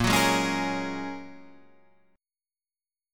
A#m7#5 chord